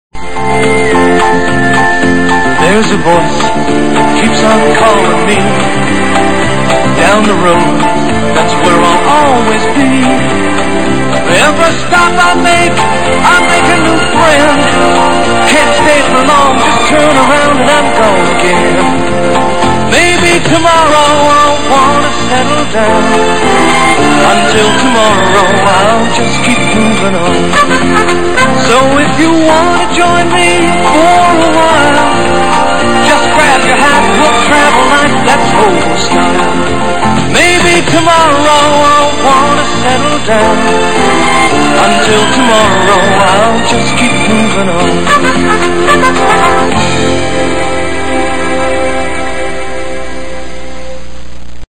It was sooooo cheesy.